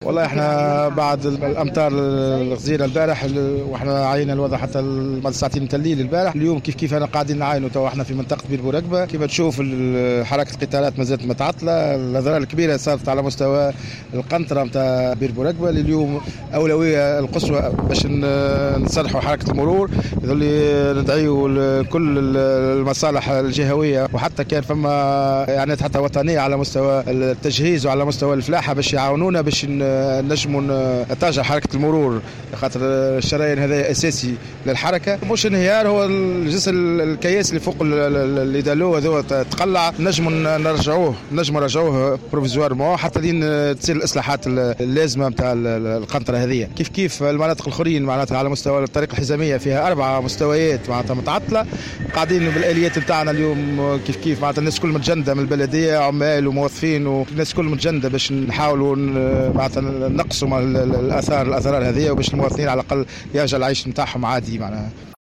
قال معز مراد رئيس بلدية الحمامات إن حركة المرور مازالت تشهد شللا تاما على مستوى جسر بئر بورقبة، وكذلك على مستوى الطريق الحزامية.